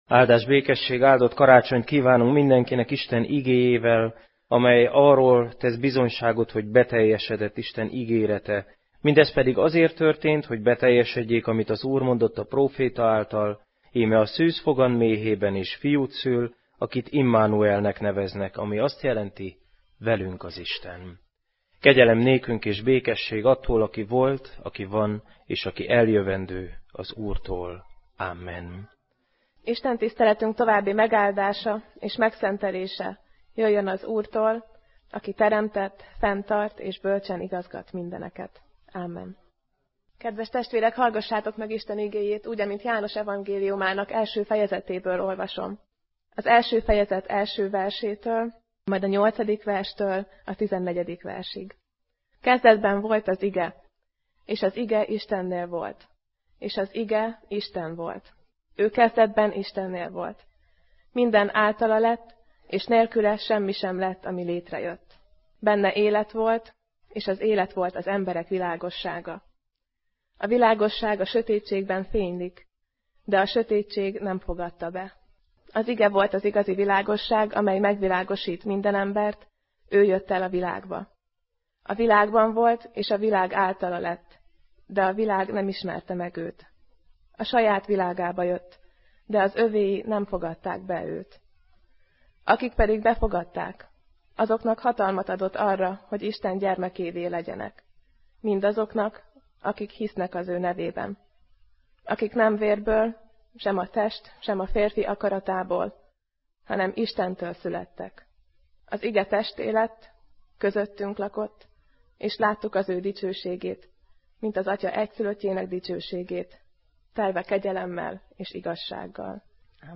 2015 december 26, szombat délelőtt; Karácsonyi Istentisztelet